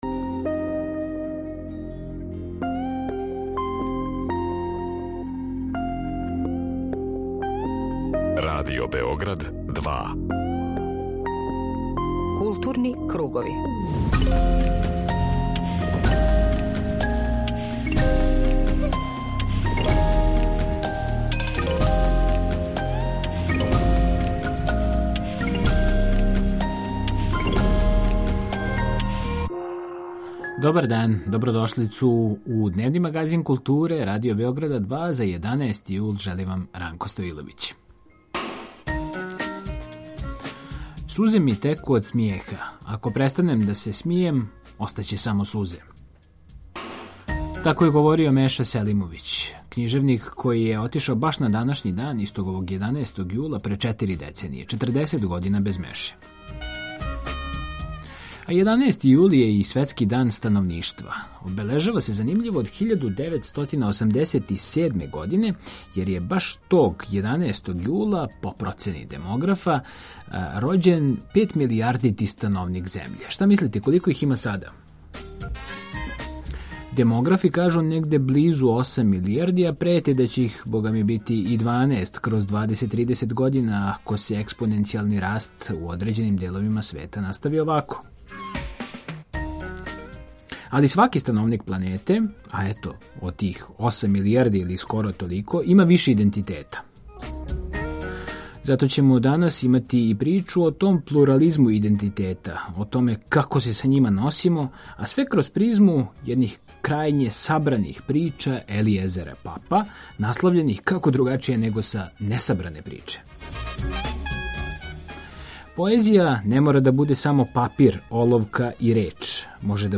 Централна културно-уметничка емисија Радио Београда 2.
разговор